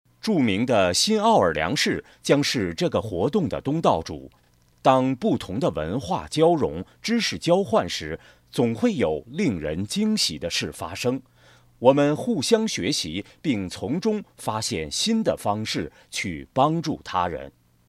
Best Voice in Chinese (Mandarin) Warm, Bright, Deep, Smooth and Professional.
Sprechprobe: Werbung (Muttersprache):